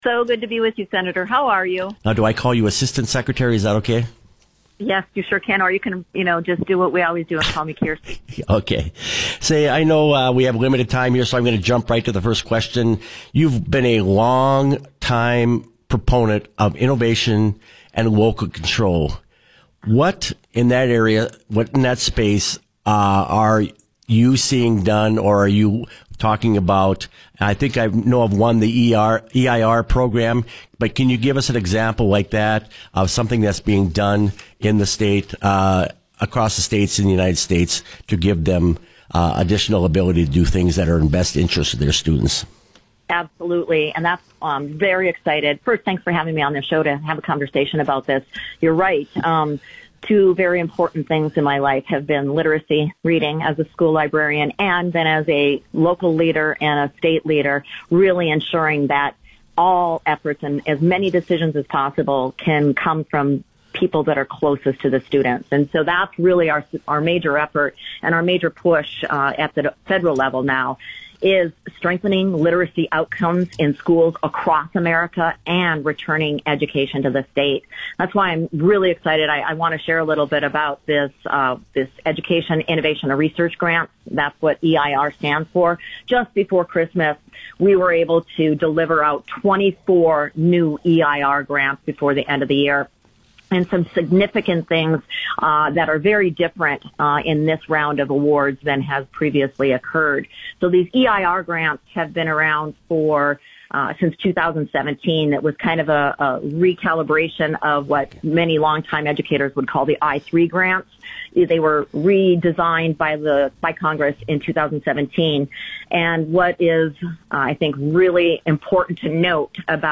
LISTEN: Tim Flakoll’s interview with Assistant Secretary for Elementary and Secondary Education Kirsten Baesler